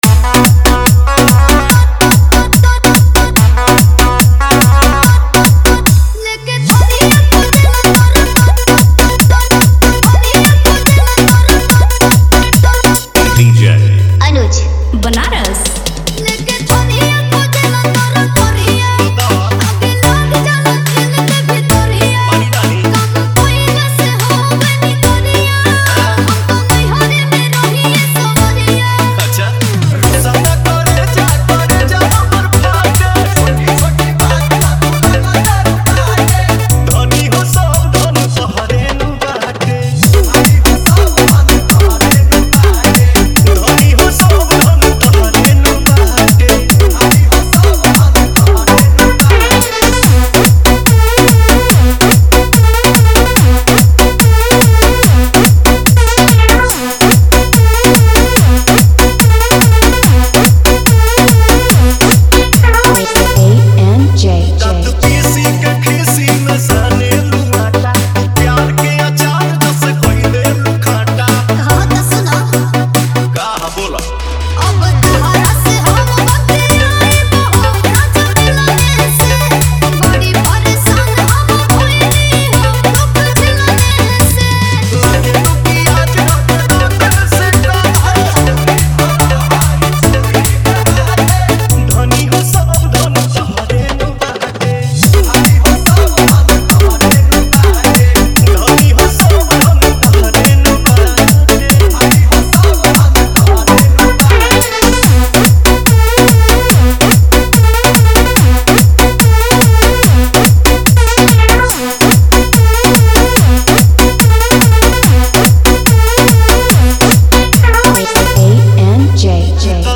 New Bhojpuri Dj Remix